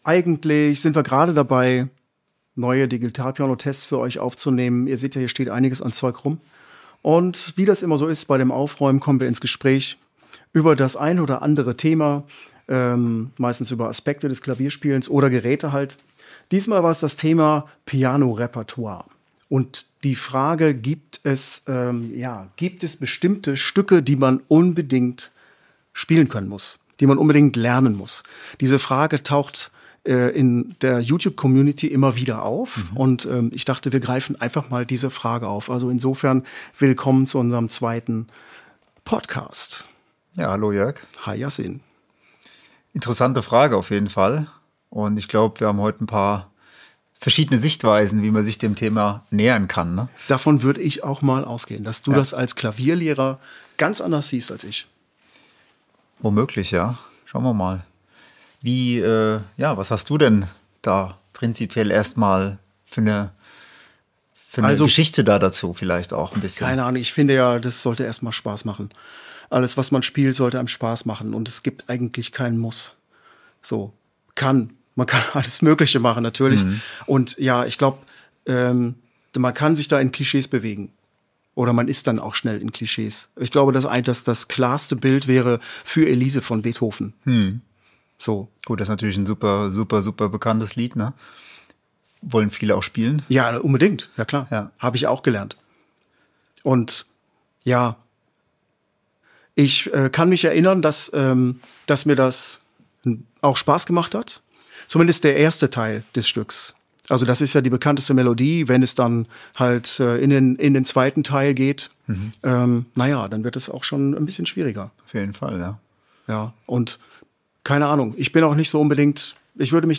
In unserem Gespräch stellen wir fest, dass man auf jeden Fall unterschiedliche POVs haben kann... nicht nur über die Stücke, sondern auch über die Herangehensweise.